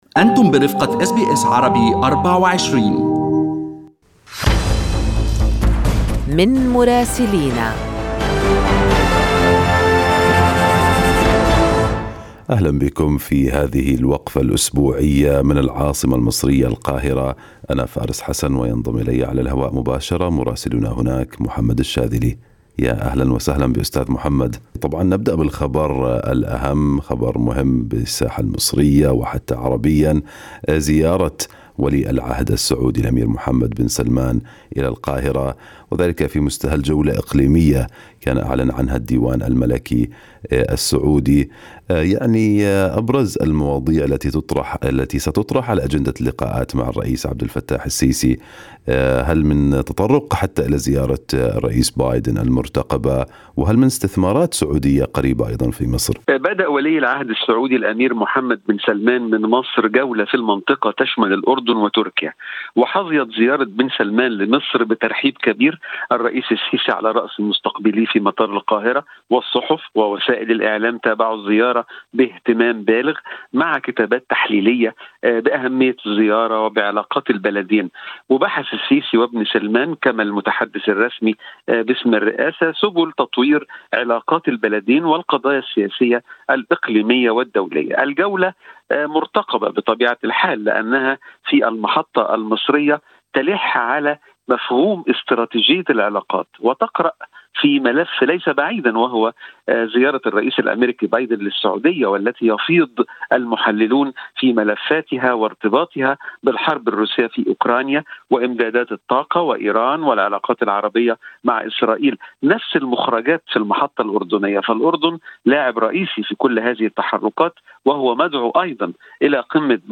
من مراسلينا: أخبار مصر في أسبوع 22/6/2022